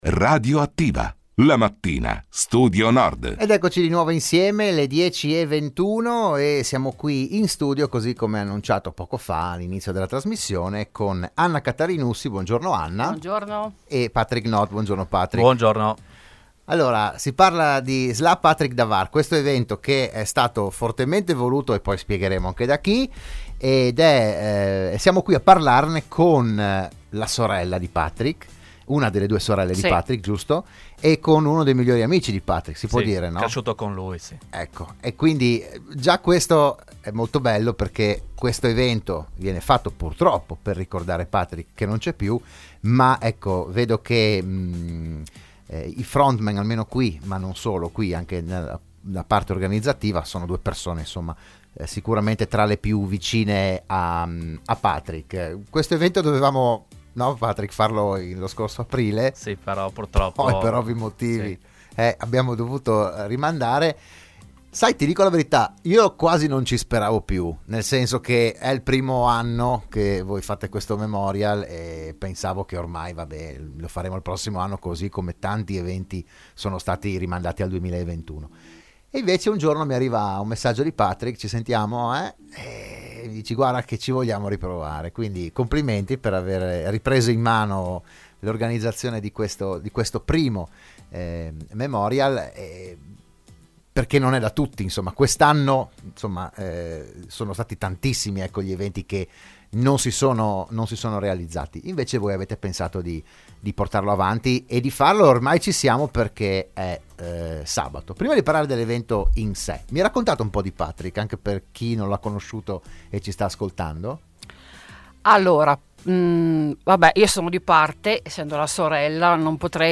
L'AUDIO e il VIDEO dell'intervento a Radio Studio Nord